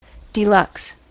En-us-deluxe.spx